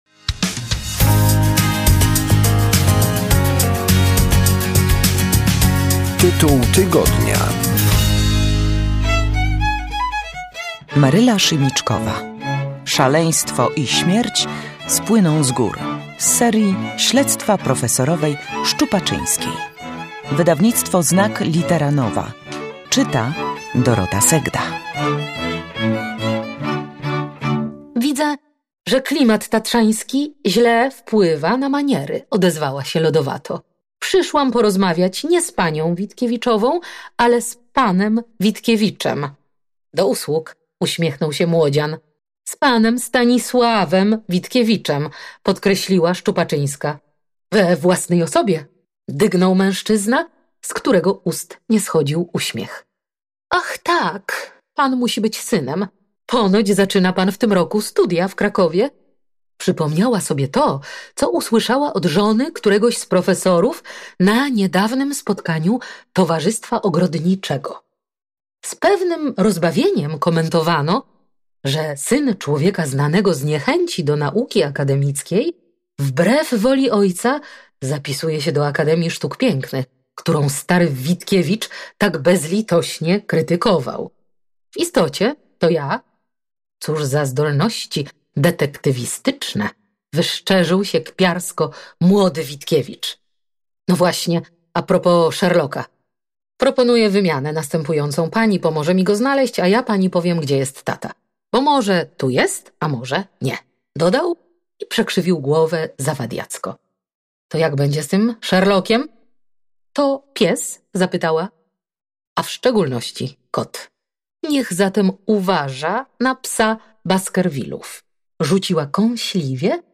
Maryla Szymiczkowa Szaleństwo i śmierć spłyną z gór z serii: Śledztwa profesorowej Szczupaczyńskiej Wydawnictwo Znak Litera Nowa czyta Dorota Segda